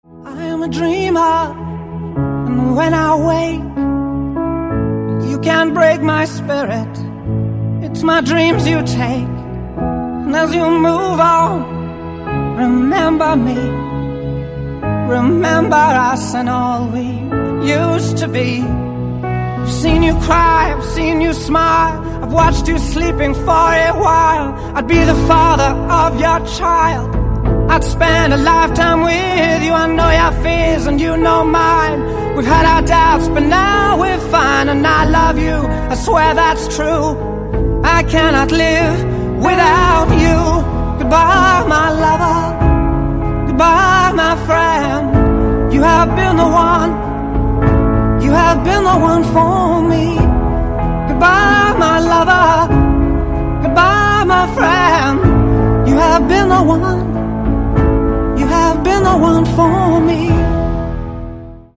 • Качество: 128, Stereo
мужской вокал
грустные
спокойные
клавишные
soul
Soft rock
акустика